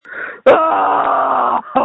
Screams from December 24, 2020
• When you call, we record you making sounds. Hopefully screaming.